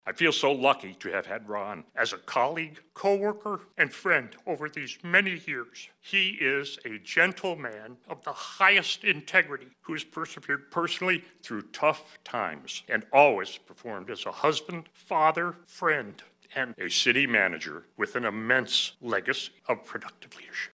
Several of Fehr’s colleagues, as well as family and friends, gathered Thursday at a public retirement reception, including former Manhattan mayor and commissioner Bruce Snead.